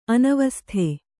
♪ anavasthe